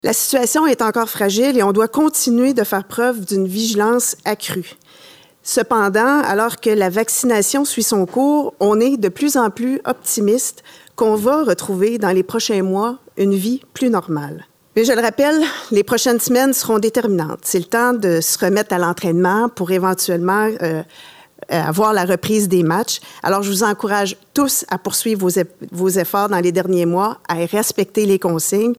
La ministre déléguée à l’Éducation, Isabelle Charest en a fait l’annonce en conférence de presse, dans le cadre du retour […]